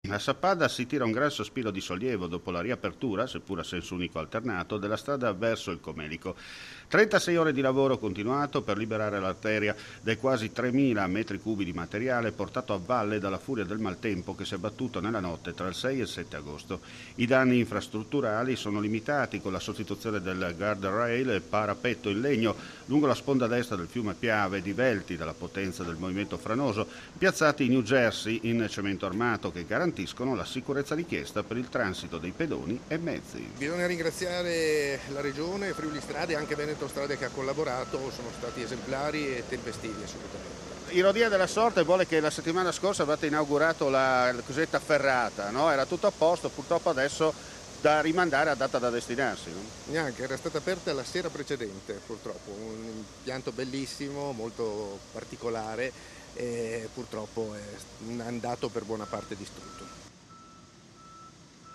FRIULITV GIORNALE RADIO: LE ULTIME NOTIZIE DAL FRIULI VENEZIA GIULIA
RIAPERTA ALLE ORE 15 DI GIOVEDI’ 8 AGOSTO IL COLLEGAMENTO STRADALE TRA SAPPADA E IL COMELICO. Le parole di Alessandro De Zordo Sindaco di Sappada
Situazione difficile come ci conferma Riccardo Riccardi Assessore Regionale alla Protezione Civile Fvg